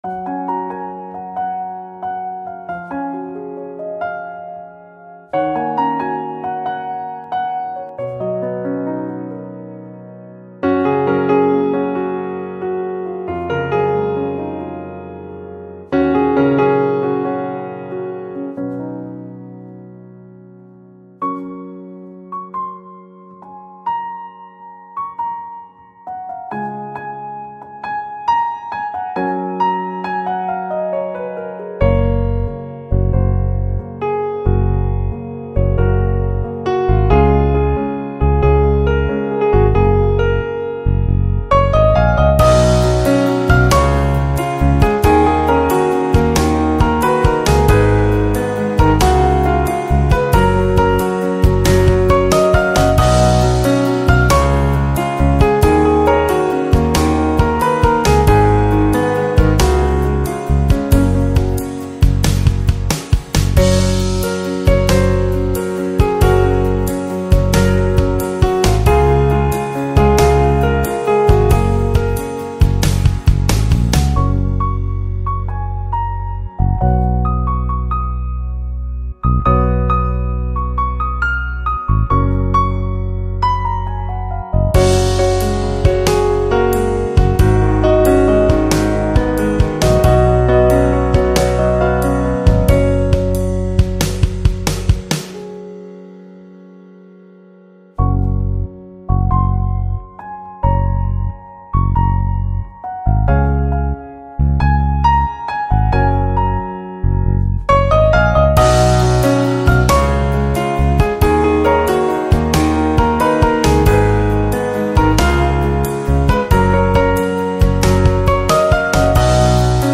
odia lofi song Songs Download